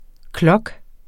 Udtale [ ˈklʌg ]